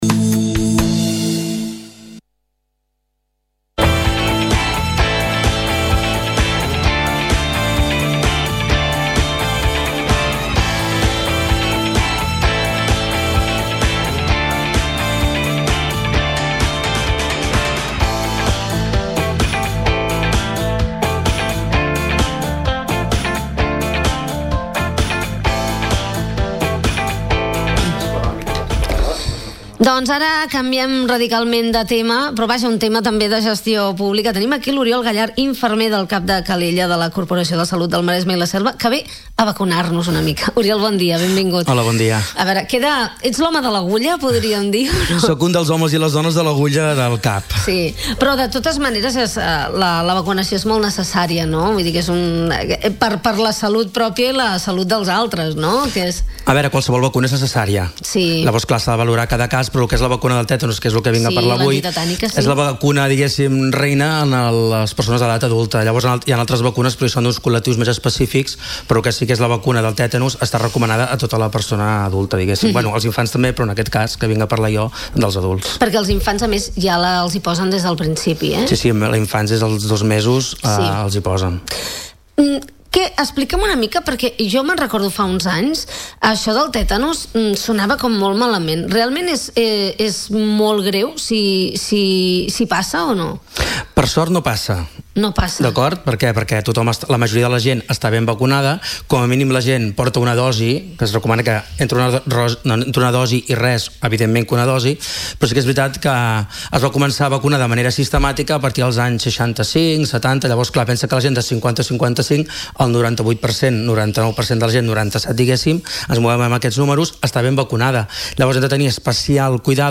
Al programa La Ciutat hem parlar amb